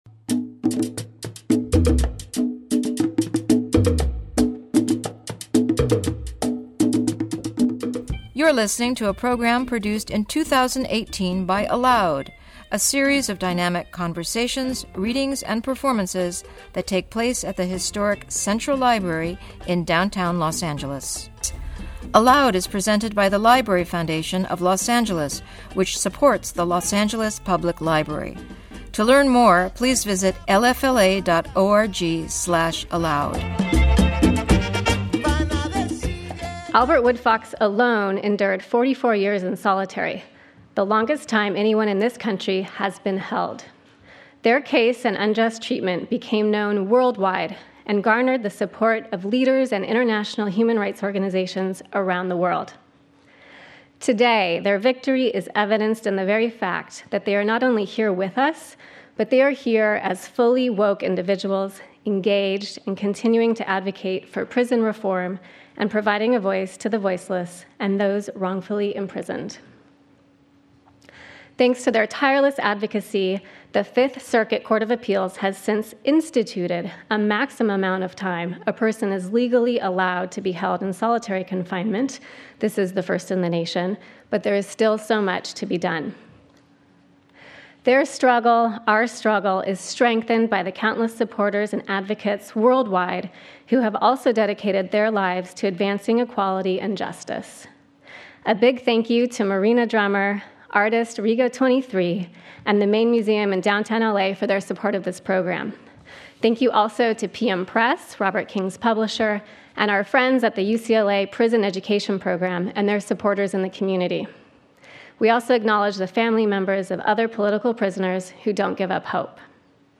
Robert King and Albert Woodfox In Conversation